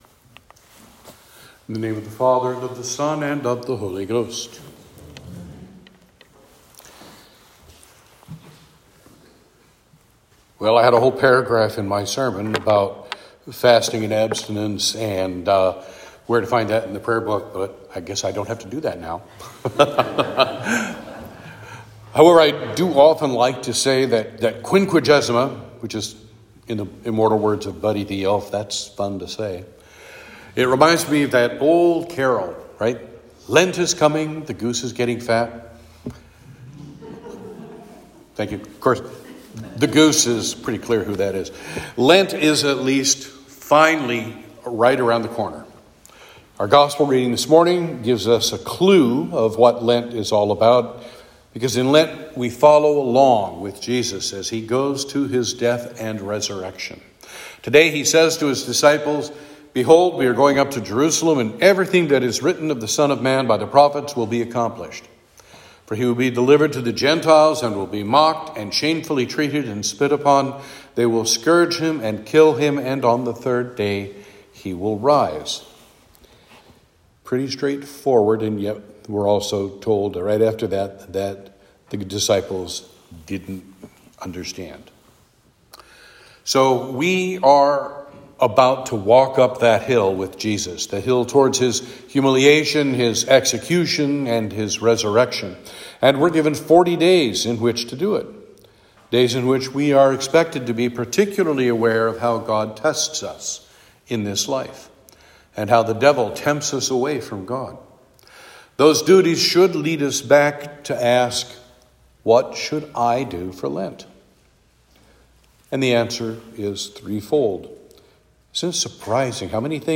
Saint George Sermons Sermon for Quinquagesima